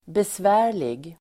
Uttal: [besv'ä:r_lig]